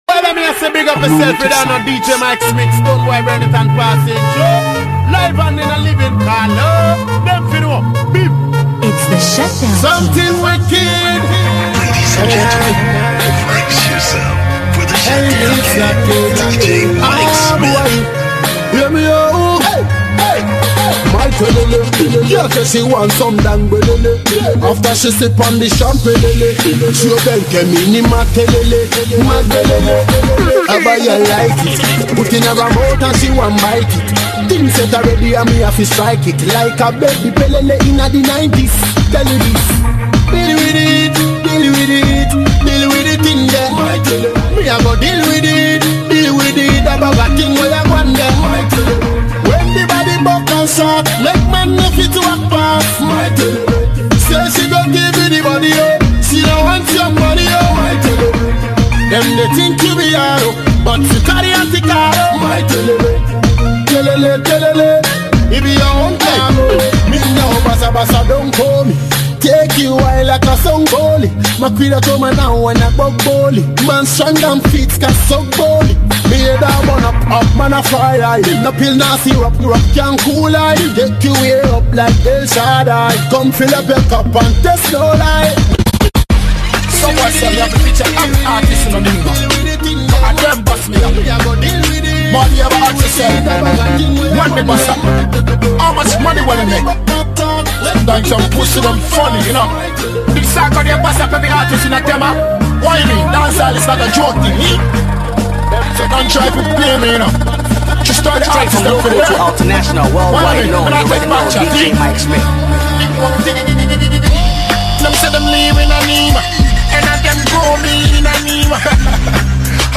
Genre: Mixtape